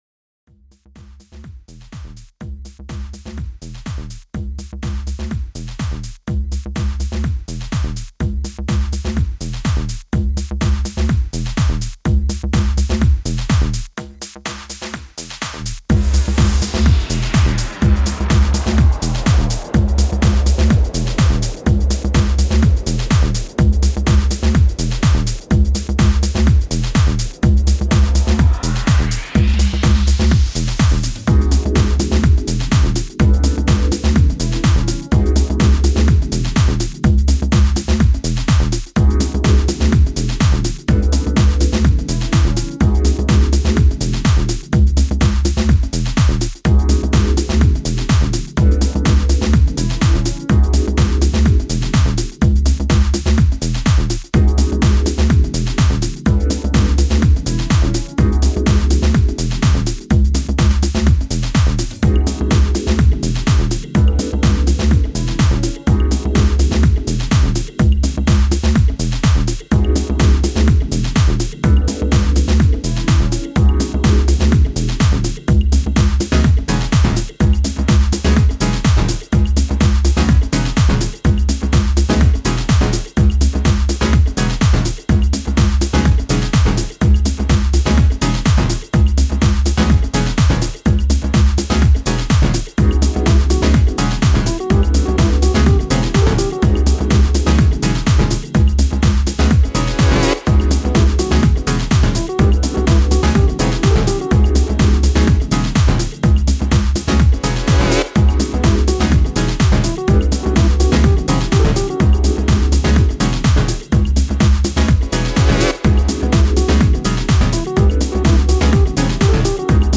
Mix Sets